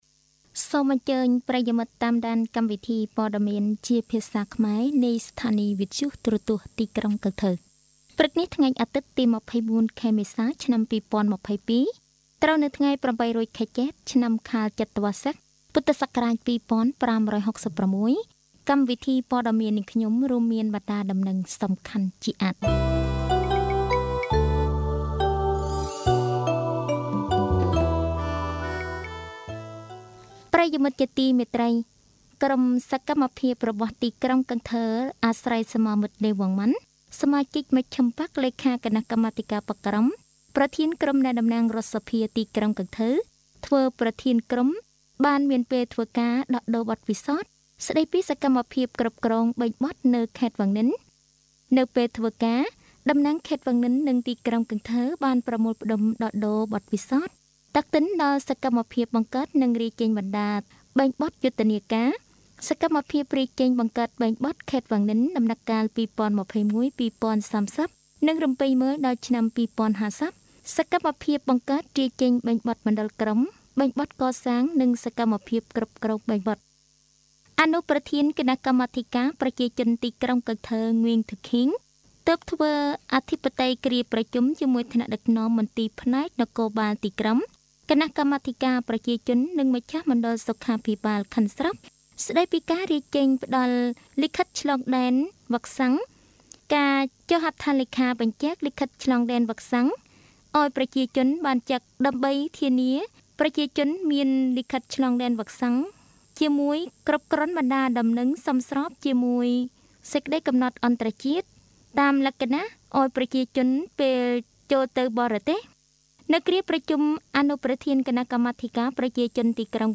Bản tin tiếng Khmer sáng 24/4/2022
Mời quý thính giả nghe Bản tin tiếng Khmer của Đài Phát thanh và Truyền hình thành phố Cần Thơ.